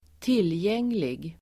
Uttal: [²t'il:jeng:lig]